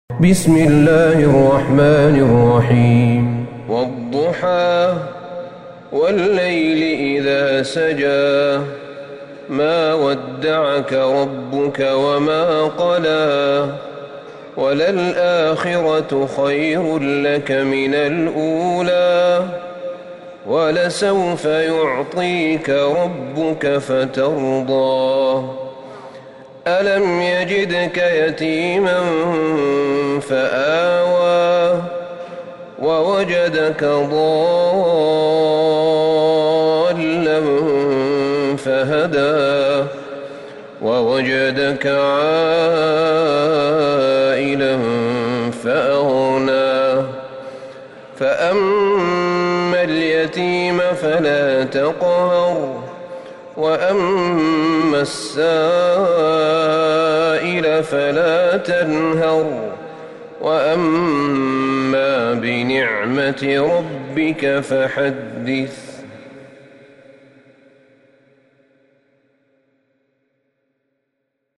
سورة الضحى Surat Ad-Duha > مصحف الشيخ أحمد بن طالب بن حميد من الحرم النبوي > المصحف - تلاوات الحرمين